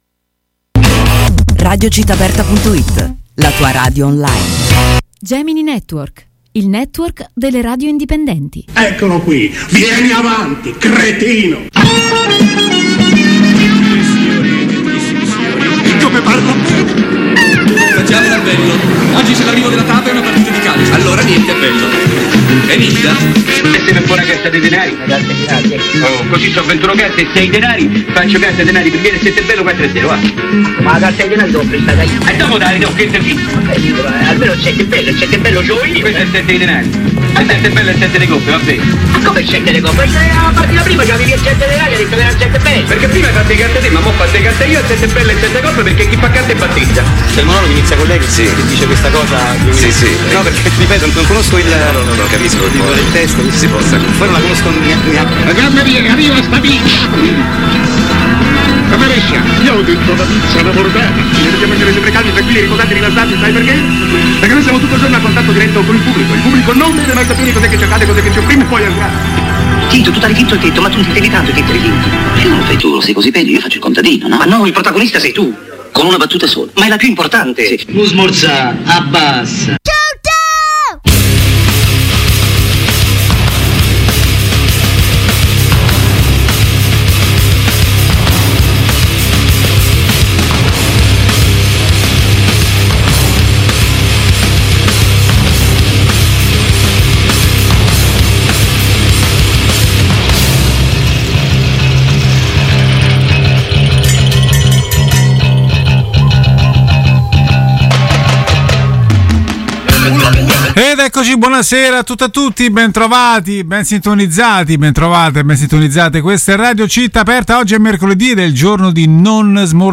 Prima delle brevi vacanze agostane ecco la consueta puntata del mercoledì di " Non Smorza Abbassa " con un miscuglio di musica inglese, internazionale e italiana scelta da me e da voi.